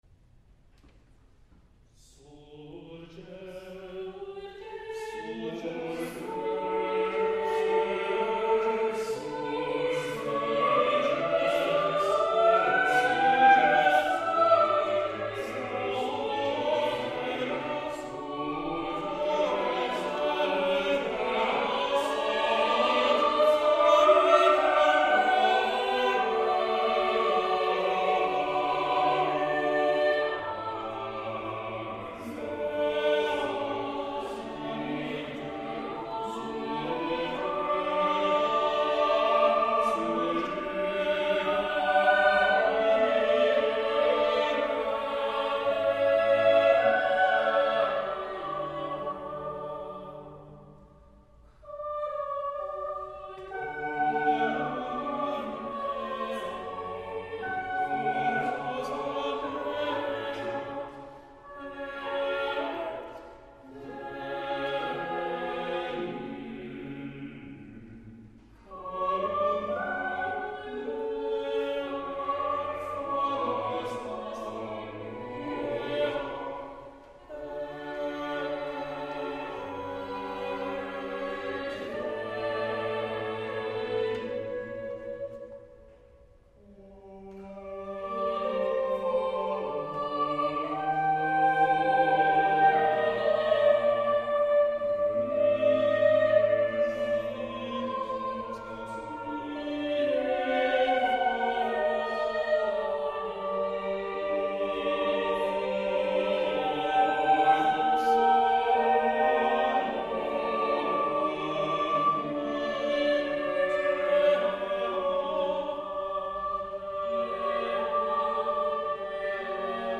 It is a choral work for 8 voices (ie, 2 SATB choirs).